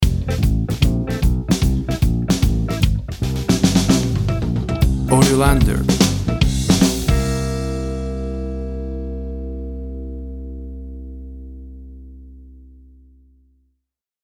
Tempo (BPM) 152